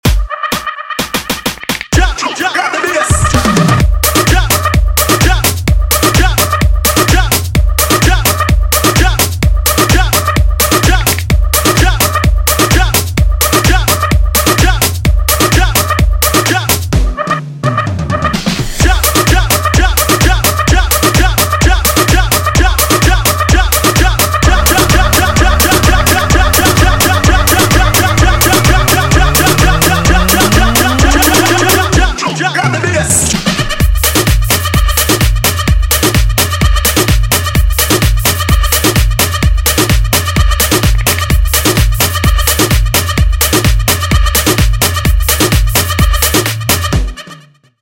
guaracha